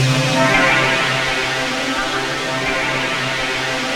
RAINY DAY.wav